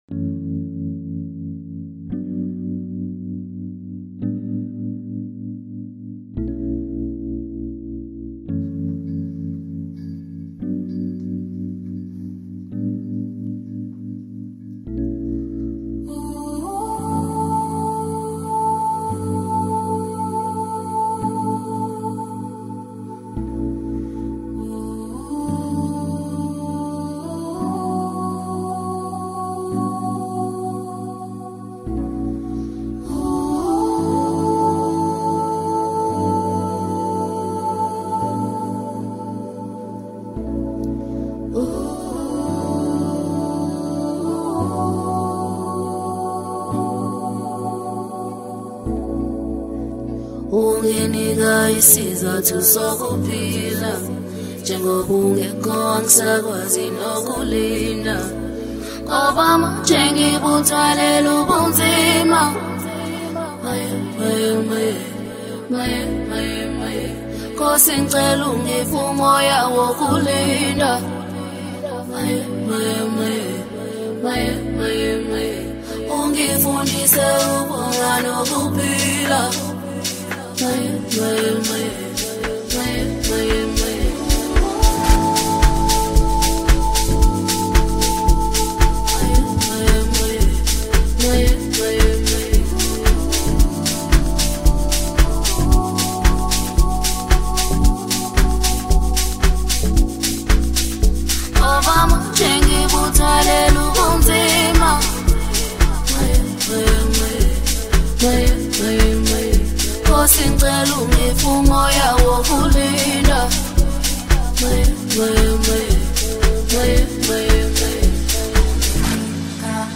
AMAPIANO MIX